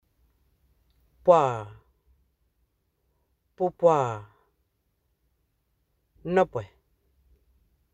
Accueil > Prononciation > pw > pw